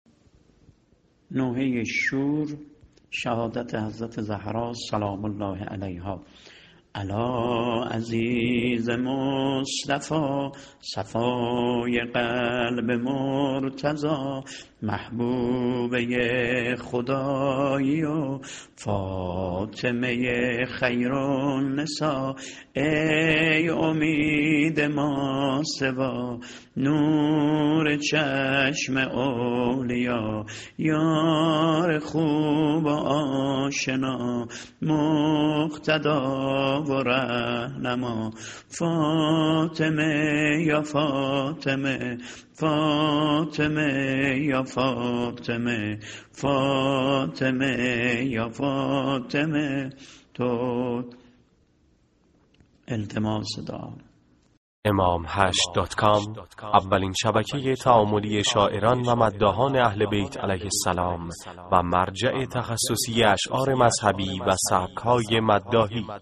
متن شعرو سبک شور شهادت حضرت زهرا(س) -(الاعزیز مصطفی صفای قلب مرتضی )